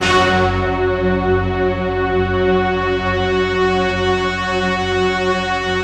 Index of /90_sSampleCDs/Optical Media International - Sonic Images Library/SI1_StaccatoOrch/SI1_Sfz Orchest